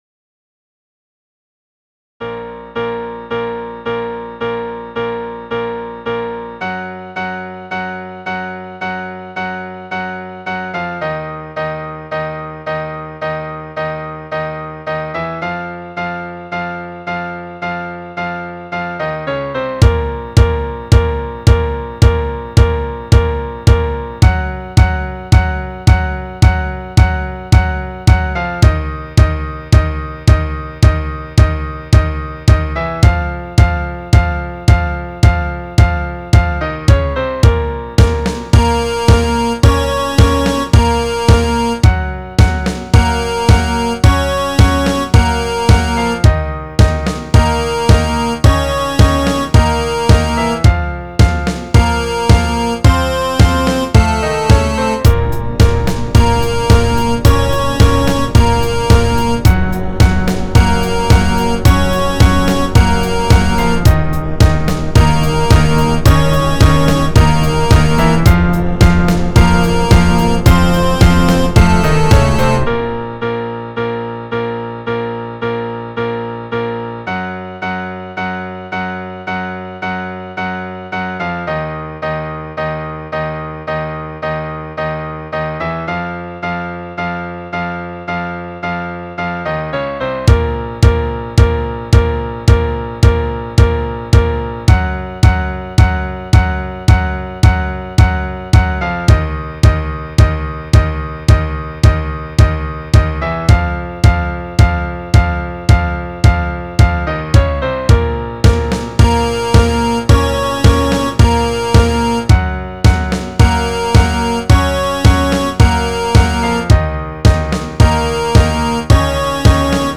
I heard this at a football game last year and taught my band to play it by ear (I use scale steps for instantanious songs)before the game was over. The only problem: I never learned the name of this little ditty.